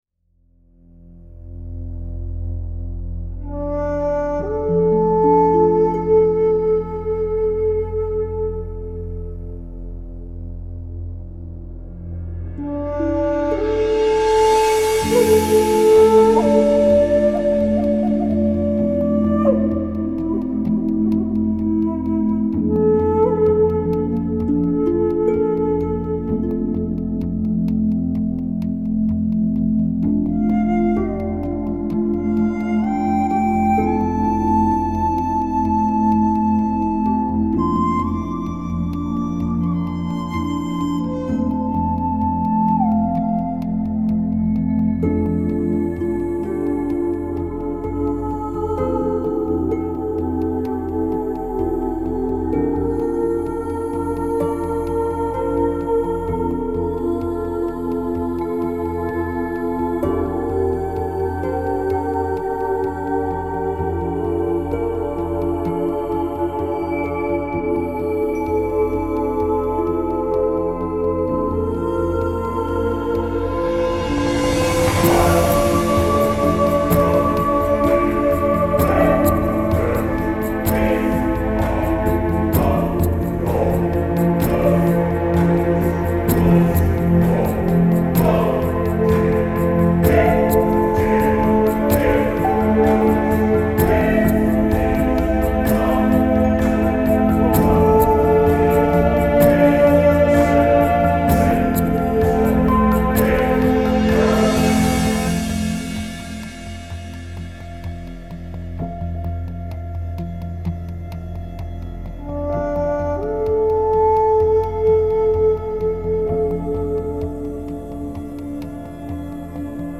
musique relaxation